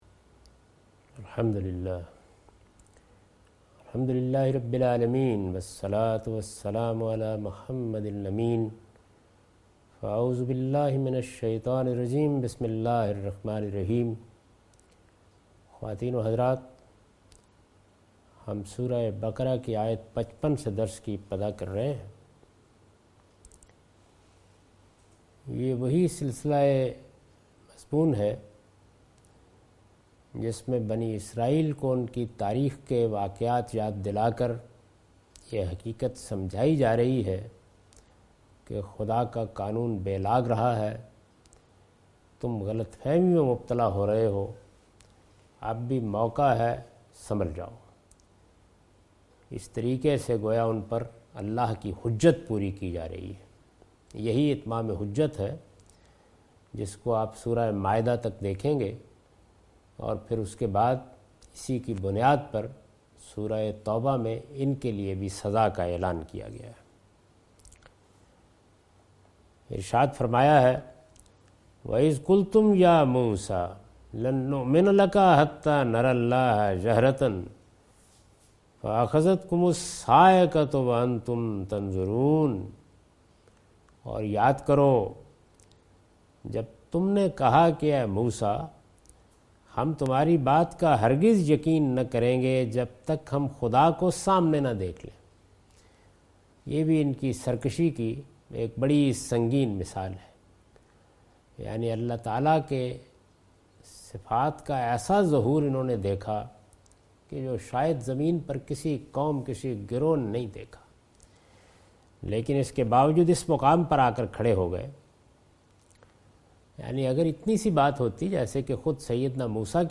Surah Al-Baqarah - A lecture of Tafseer-ul-Quran – Al-Bayan by Javed Ahmad Ghamidi. Commentary and explanation of verse 55,56,57,58,59,60 and 61 (Lecture recorded on 30th May 2013).